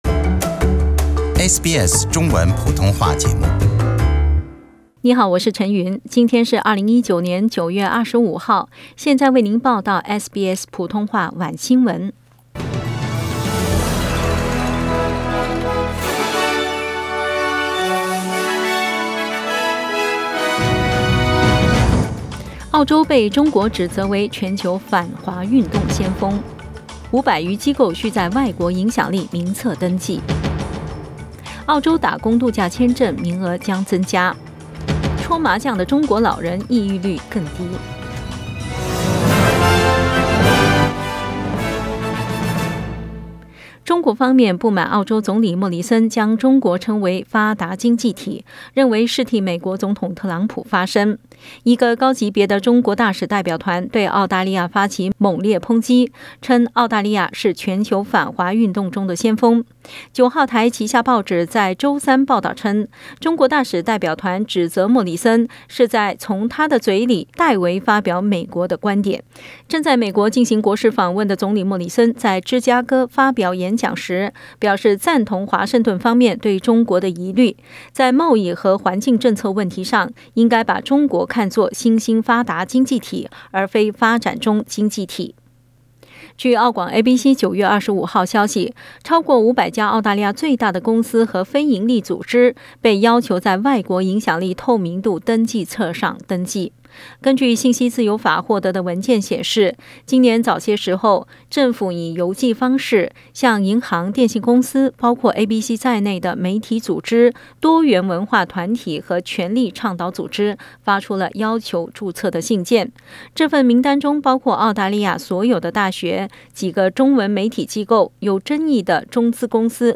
SBS晚新闻 （9月25日）